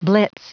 Prononciation du mot blitz en anglais (fichier audio)
Prononciation du mot : blitz